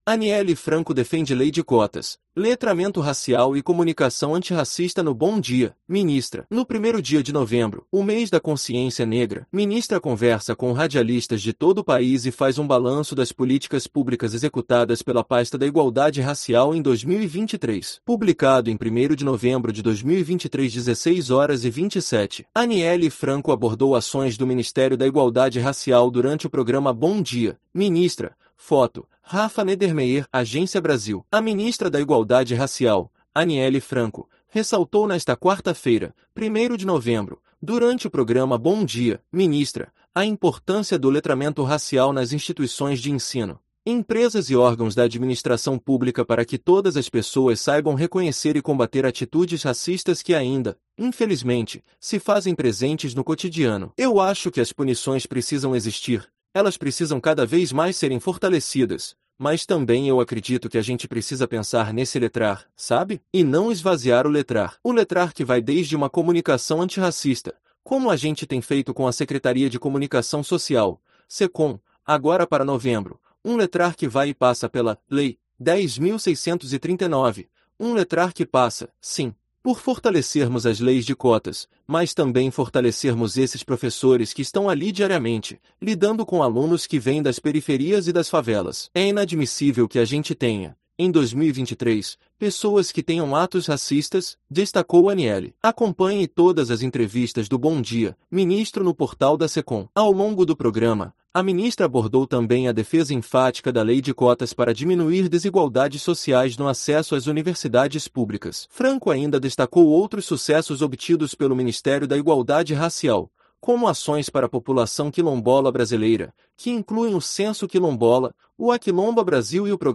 No primeiro dia de novembro, o mês da Consciência Negra, ministra conversa com radialistas de todo país e faz um balanço das políticas públicas executadas pela pasta da Igualdade Racial em 2023